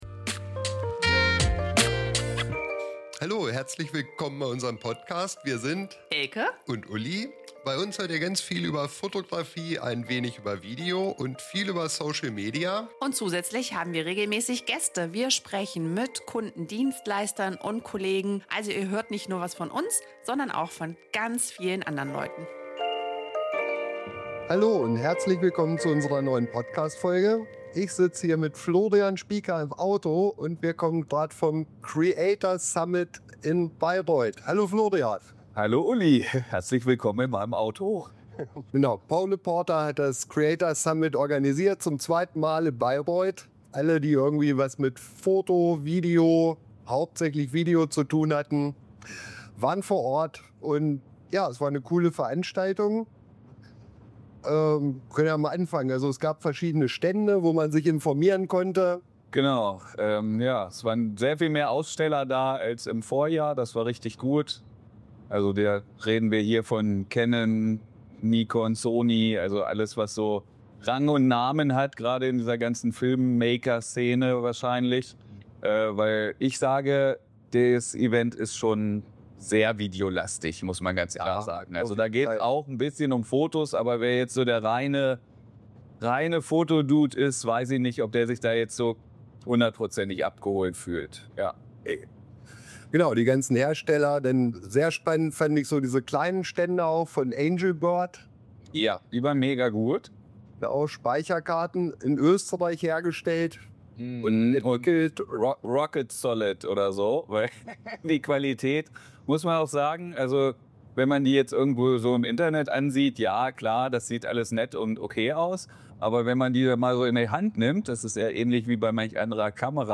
Auf der Fahrt nach Hause haben wir unsere ersten Eindrücke einmal für euch festgehalten.